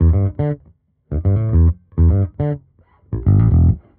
Index of /musicradar/dusty-funk-samples/Bass/120bpm
DF_JaBass_120-E.wav